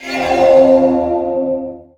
Magic_Spell14.wav